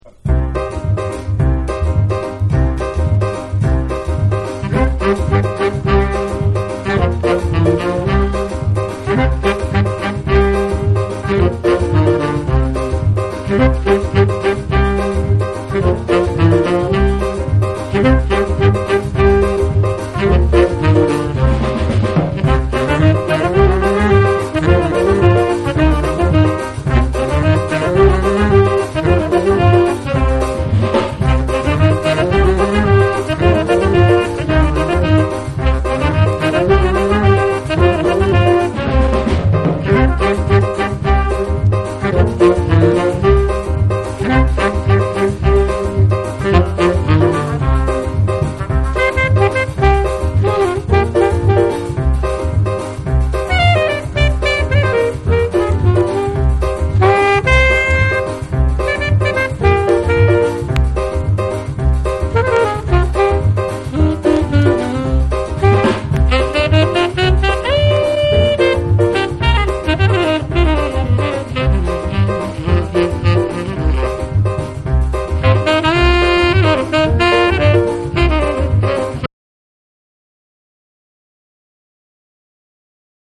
ジャジーな演奏が光るキラー・カリビアン・ナンバー
REGGAE & DUB